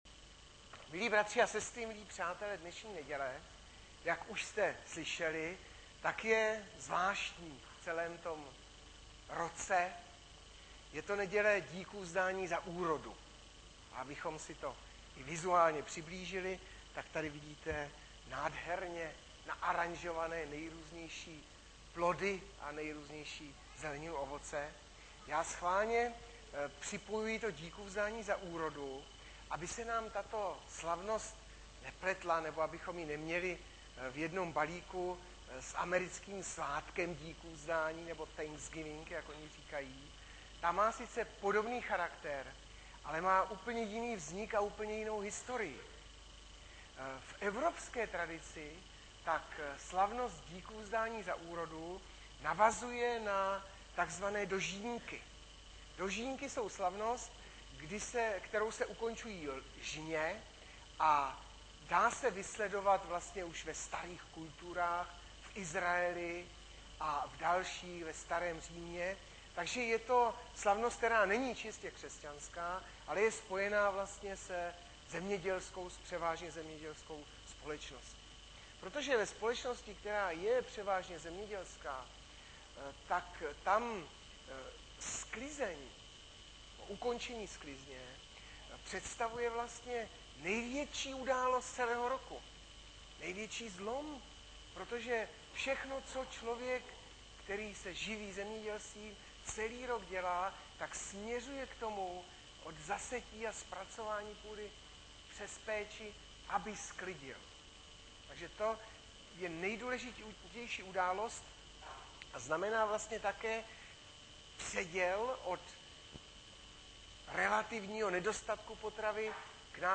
Webové stránky Sboru Bratrské jednoty v Litoměřicích.
Hlavní nabídka Kázání Chvály Kalendář Knihovna Kontakt Pro přihlášené O nás Partneři Zpravodaj Přihlásit se Zavřít Jméno Heslo Pamatuj si mě  02.10.2011 - DÍKůVZDÁNÍ - Žalm 145,15-16, 2.